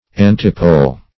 Antipole \An"ti*pole\, n.